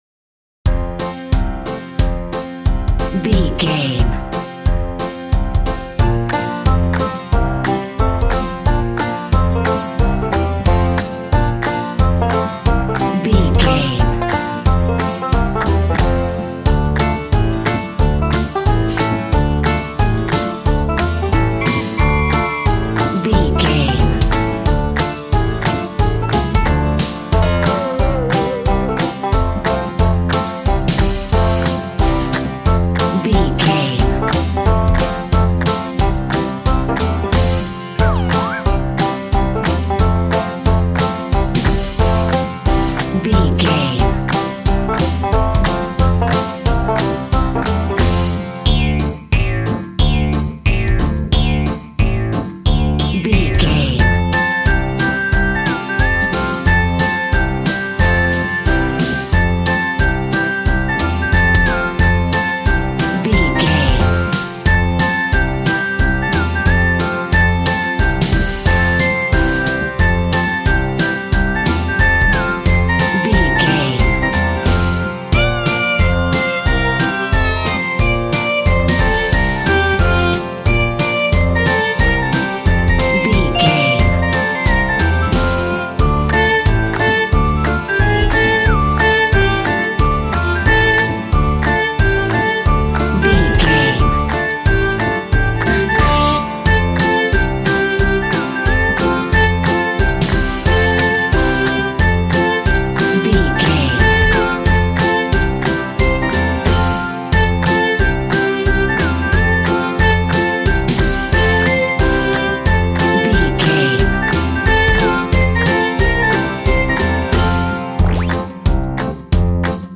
Train music for kids. A cute and bubbly piece of kids music.
Upbeat, uptempo and exciting!
Ionian/Major
cheerful/happy
bouncy
electric piano
electric guitar
drum machine